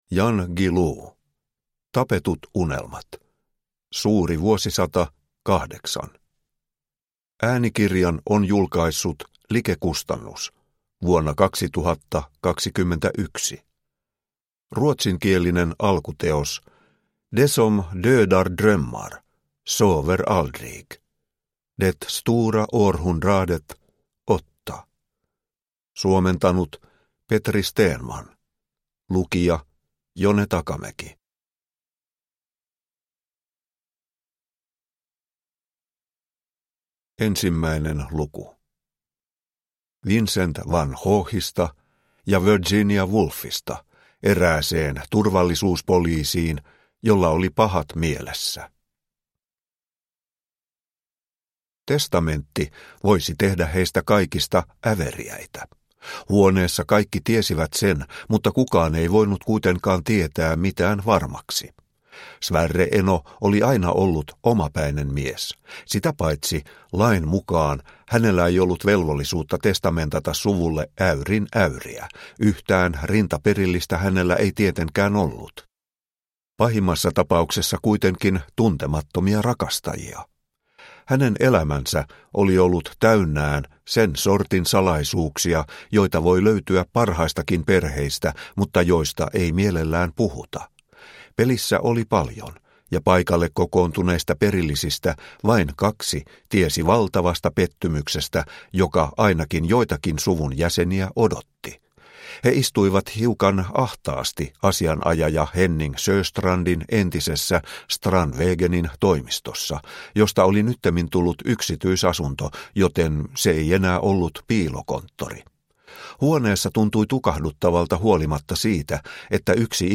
Tapetut unelmat – Ljudbok – Laddas ner